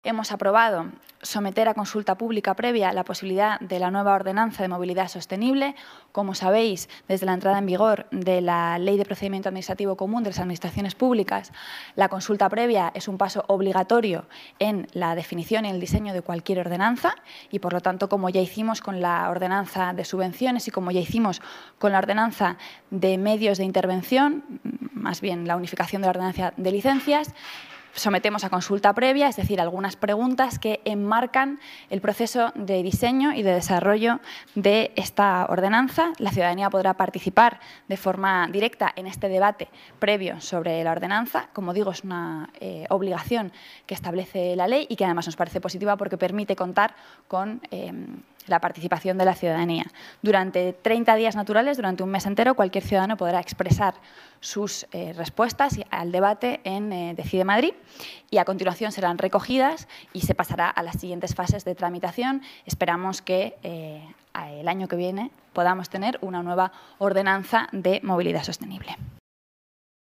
Rita Maestre señala que se ha aprobado someter a consulta previa la Ordenanza de Movilidad Sostenible en la Junta de Gobierno La portavoz municipal detalla las preguntas que aparecerán en la consulta ciudadana